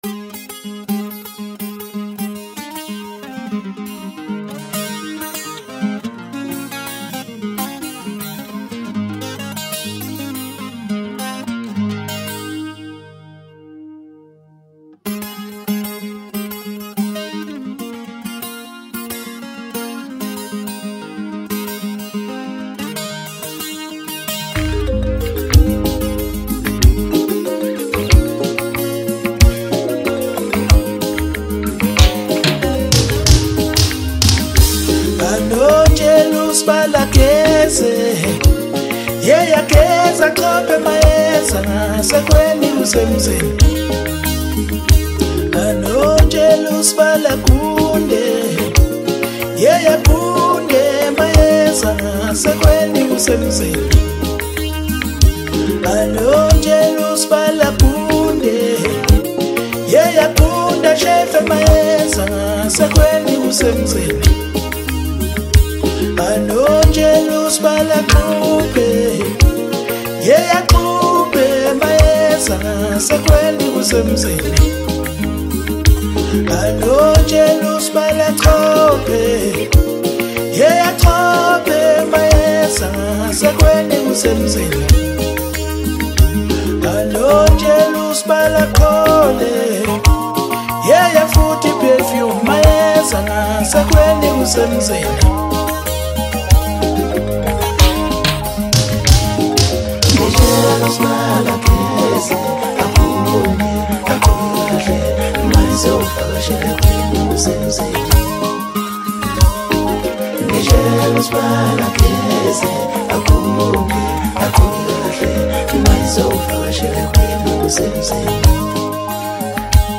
Home » Maskandi Music » Album